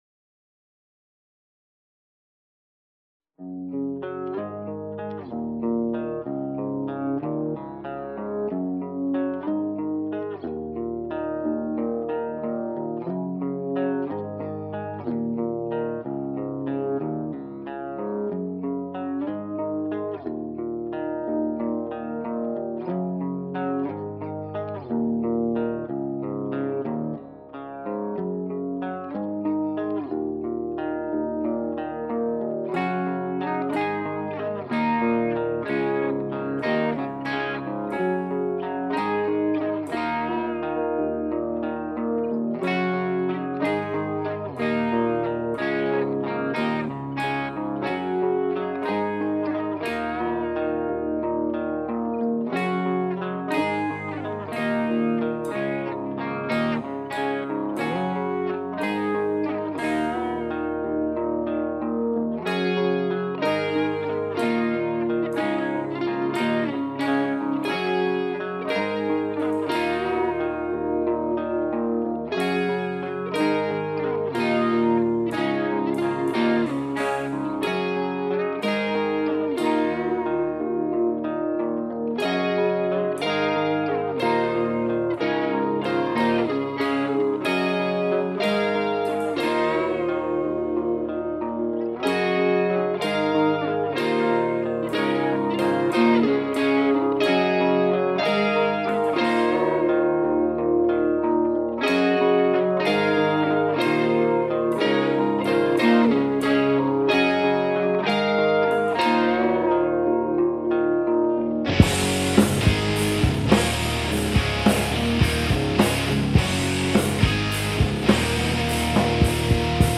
Rock & Roll
Rock/Hard-rock